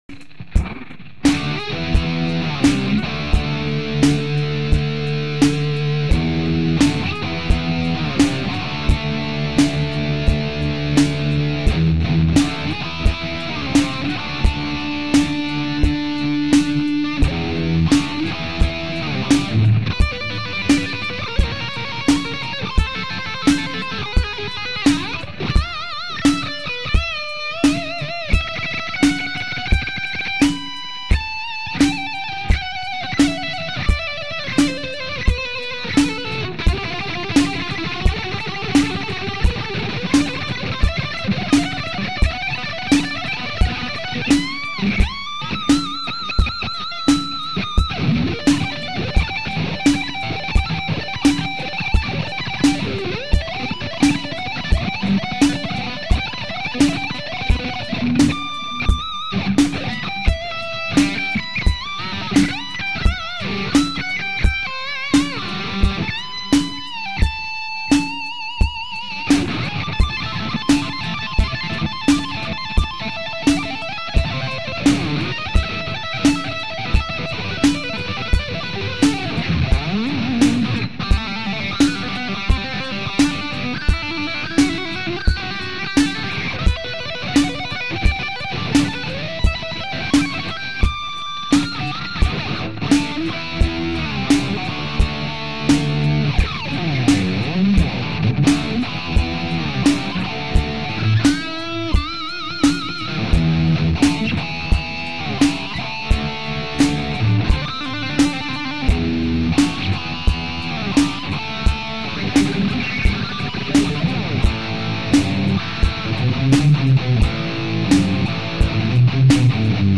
先日、コンプを外しました。
ＪＣＭ２０００+ＴＳ８０８+ショートディレイの組み合わせ、
ギブソンレスポールとＰＯＤで録音してみました。
リズムパターンは違いますが、録音してみました。